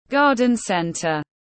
Garden centre /ˈɡɑːr.dən ˌsen.t̬ɚ/